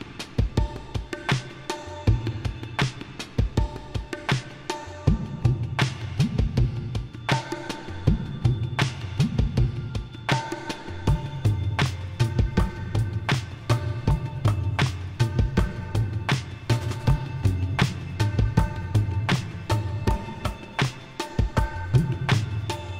Tabla Ringtones